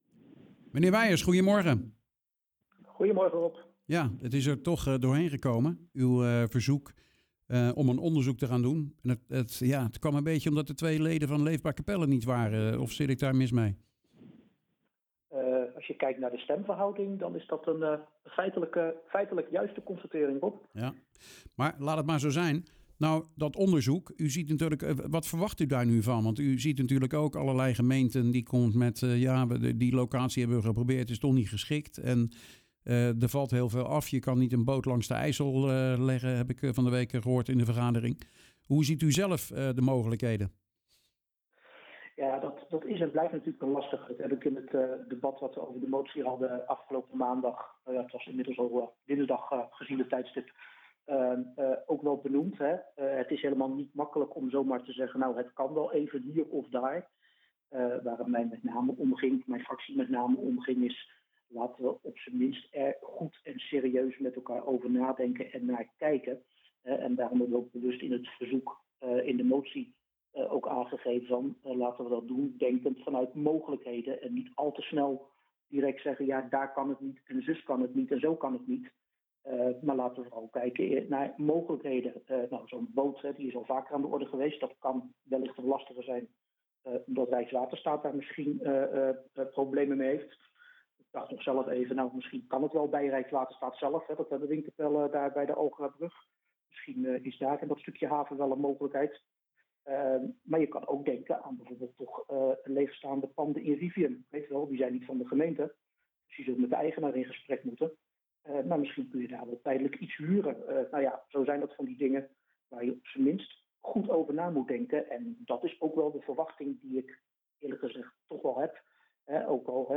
Over de tijdelijke opvangmogelijkheden voor vluchtelingen in� Capelle spraken we de afgelopen weken met SGP Raadslid Pieter Weijers.